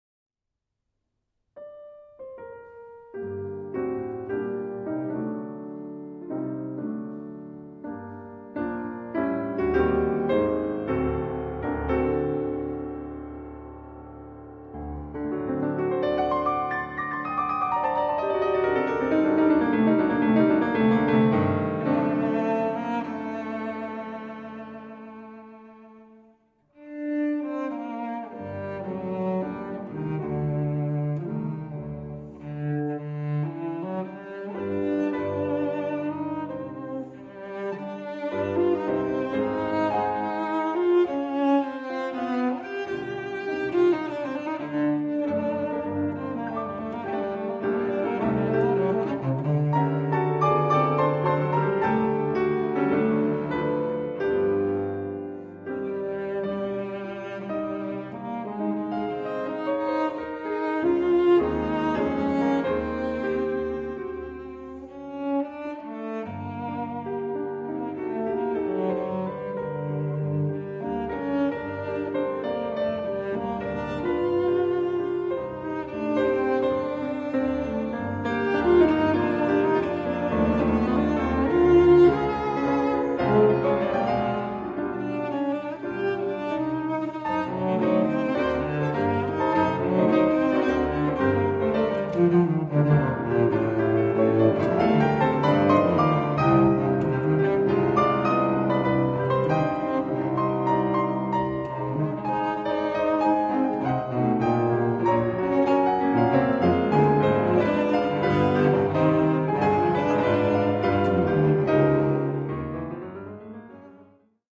Sonata g-moll na fortepian i wiolonczelę op. 65, cz. I Allegro moderato
wiolonczela
fortepian